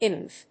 音節IMF